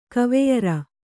♪ kaveyara